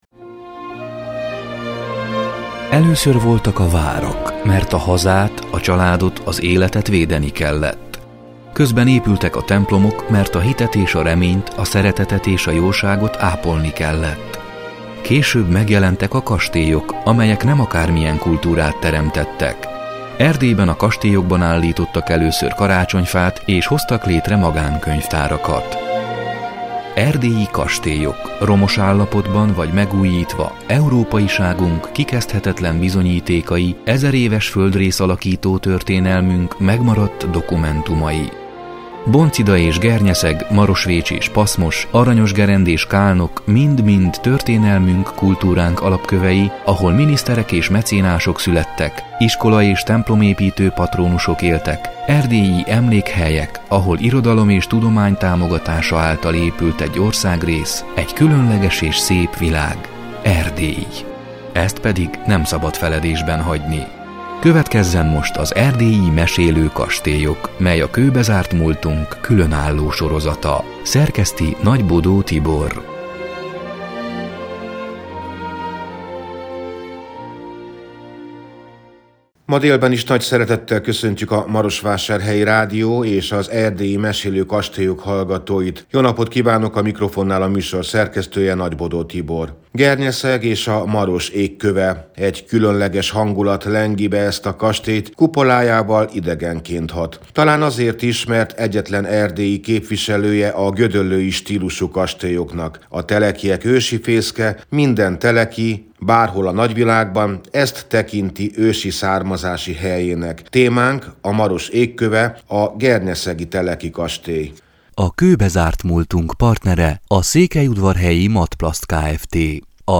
(elhangzott: 2021. szeptember 25-én, a szombat déli harangszó után)